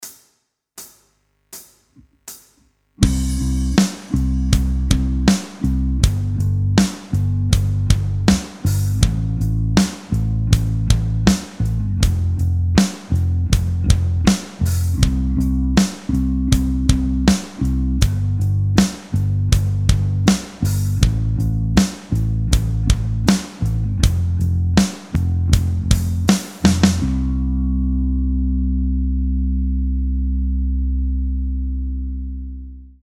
Backing-Track-7.mp3